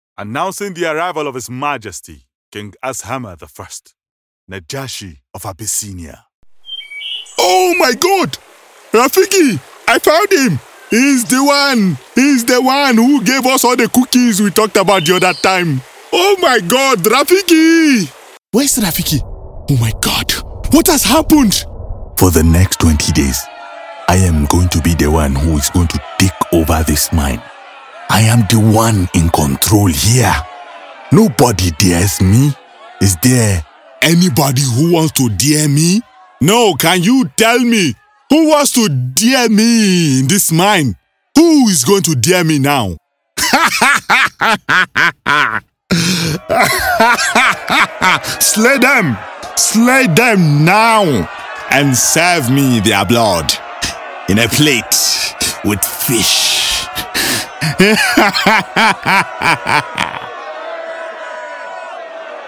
Natürlich, Zuverlässig, Warm, Kommerziell, Vielseitig
He has an authentic, articulate and clear voice which resonates with audiences across the globe.